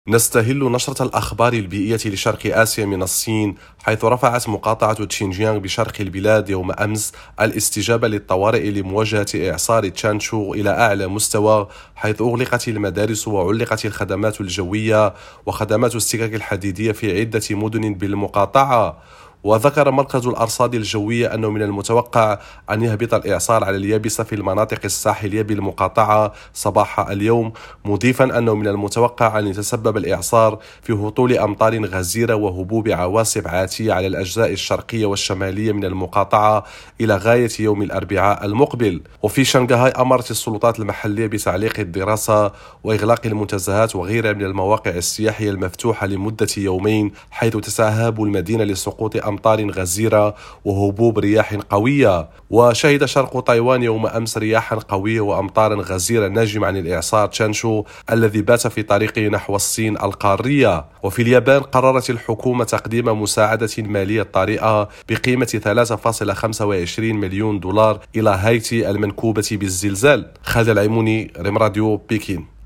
نشرة الأخبار البيئية لشرق آسيا